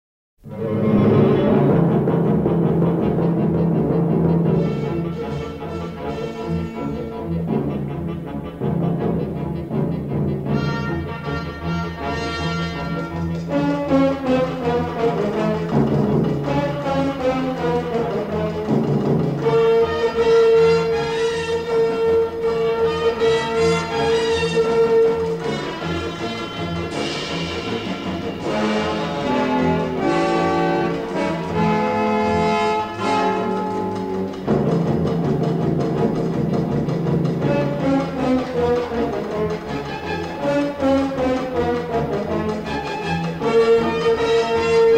remastered from the original 1/4" stereo album tapes